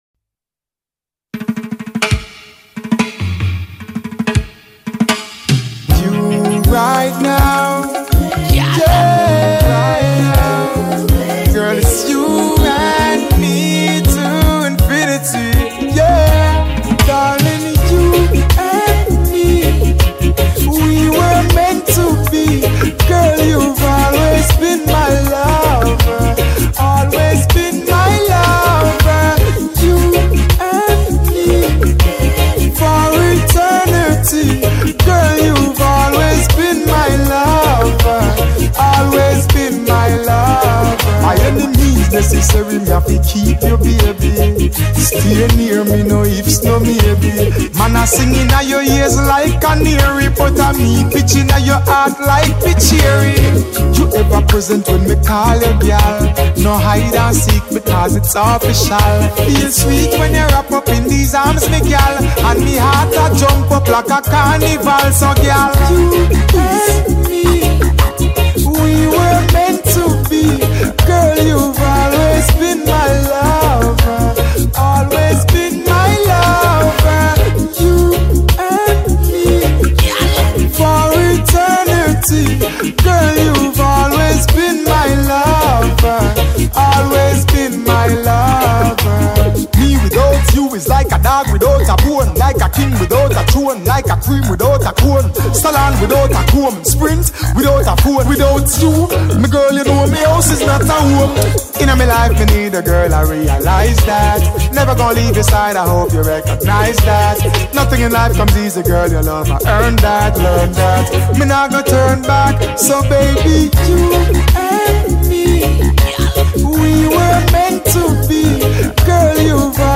Soul reggae Riddim - Jan 2013